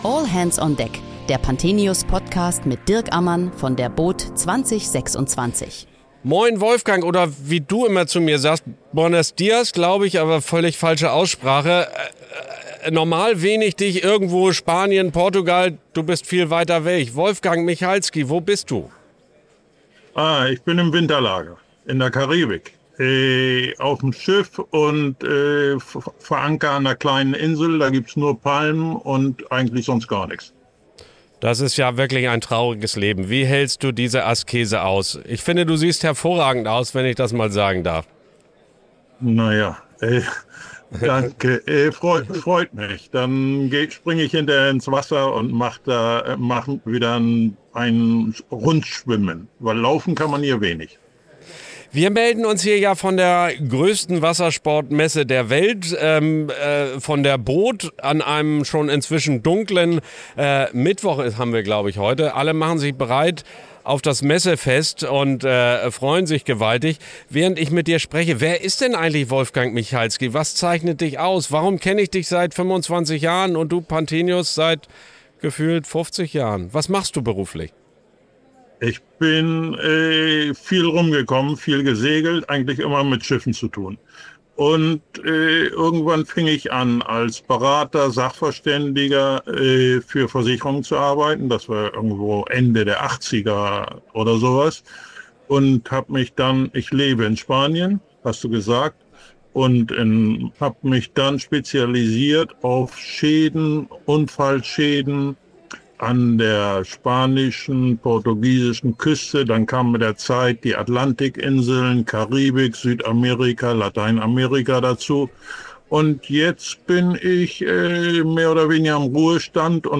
Eine Folge für alle, die sich jenseits von Schlagzeilen ein realistisches Bild vom Orca-Phänomen machen wollen – sachlich, einordnend und praxisnah. Während der boot Düsseldorf 2026 haben wir täglich mit Gästen aus der Branche über aktuelle und kontroverse Themen des Wassersports gesprochen.